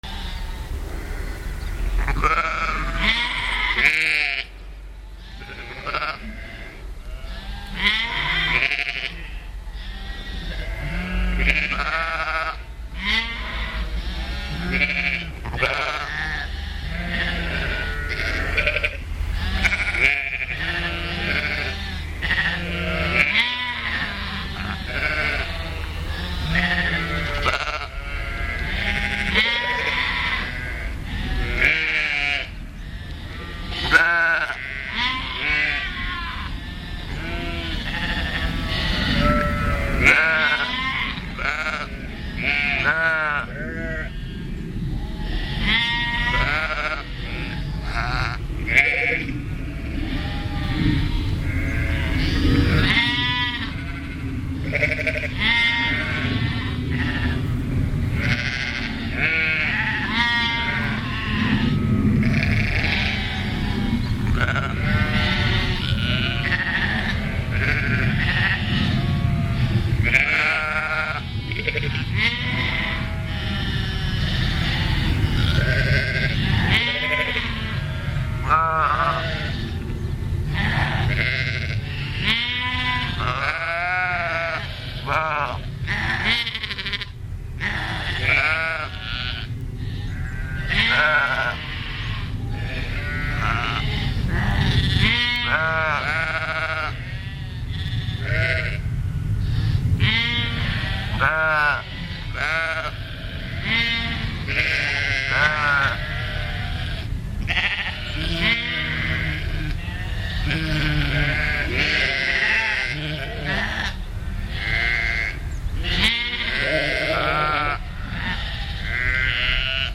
Back in Perthshire again with the Sony HiMD MiniDisc Recorder MZ-NH 1 in the PCM mode and the Soundman OKM II with the A 3 Adapter .
No wind, so no problems on that score, while doing the recording. Don´t those sheep sound human?